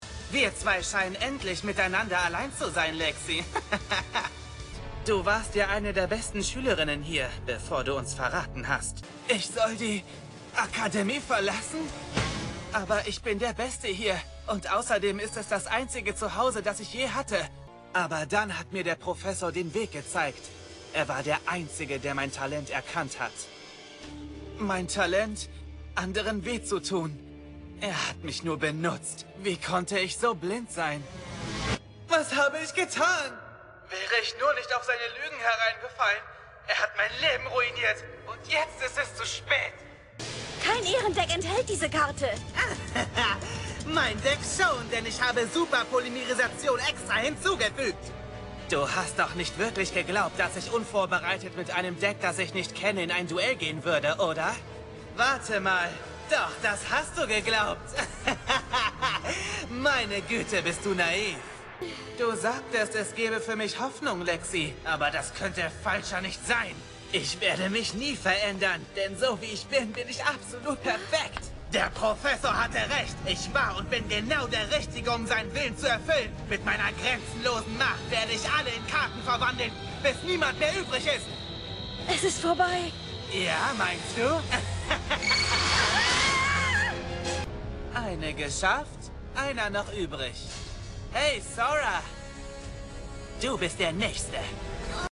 Animation
Soundproof recording booth – 38 dB in the midrange and up to 84 dB in the high frequency range